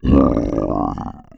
AlienDie.wav